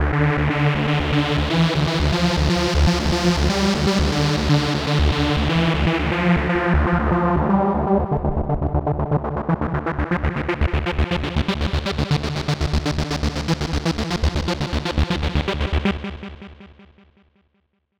komposter_supersaw2.wav